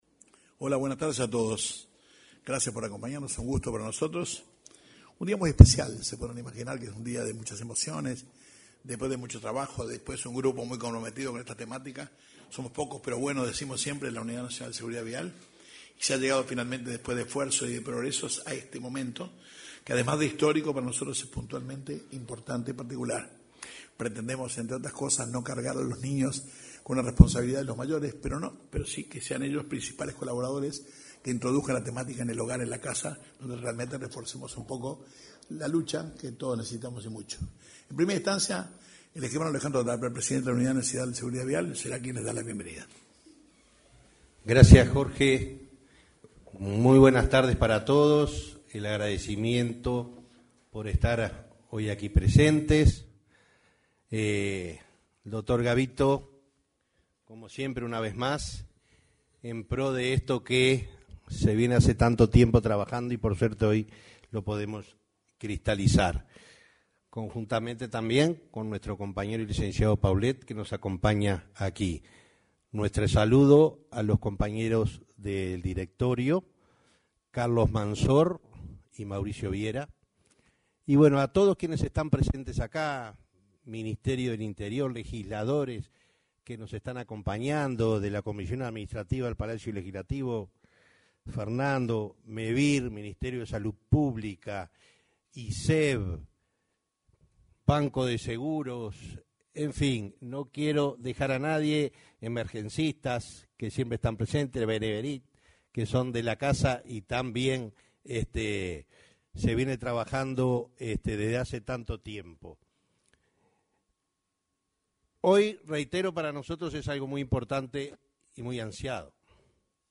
Presentación del Plan de Trabajo sobre Educación en Seguridad Vial 06/09/2022 Compartir Facebook X Copiar enlace WhatsApp LinkedIn En el salón de actos de la Torre Ejecutiva se desarrolló la presentación del Plan de Trabajo sobre Educación en Seguridad Vial en los diferentes subsistemas.